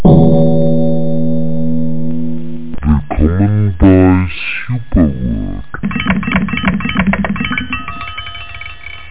Amiga 8-bit Sampled Voice
1 channel